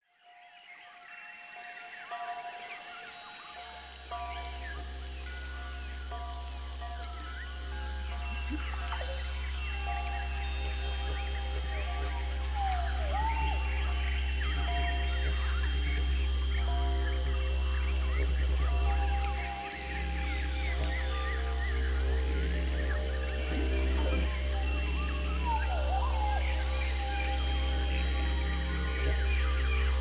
Rainforest - soothing sounds
Within minutes, you find yourself in a perfect world , where your brain is stimulated and soothed by the sounds of nature.
rainforest.mp3